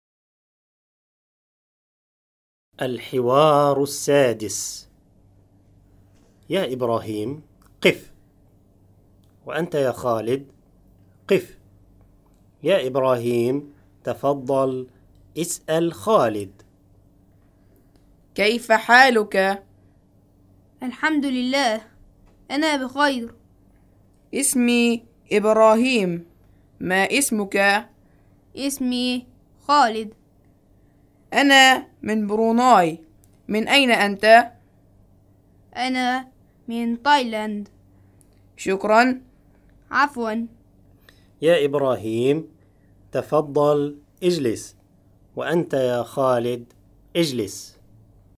(1-1-6) الحوار السادس: